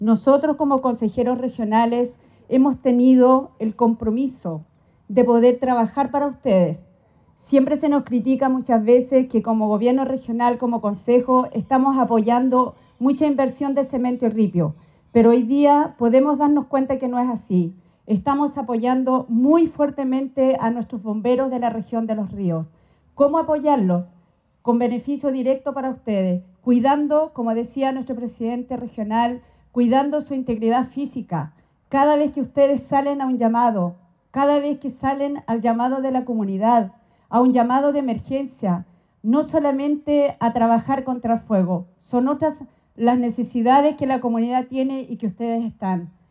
En los jardines de la casa Prochelle II, el Gobernador Regional Luis Cuvertino junto a los Consejeros Regionales Elías Sabat, Catalina Hott, Ariel Muñoz y Héctor Pacheco entregaron 312 equipos de respiración a 78 compañías de Bomberos de la Región de Los Ríos, correspondientes a Valdivia, Panguipulli, La Unión, Río Bueno, San José de la Mariquina, Futrono, Lanco, Crucero, Lago Ranco, Los Lagos, Paillaco, Choshuenco, Corral, Reumén, Malalhue, Máfil, Huellelhue, Antilhue, Mehuín y Pichirropulli.
Catalina-Hott_Core_Equipos-bomberos.mp3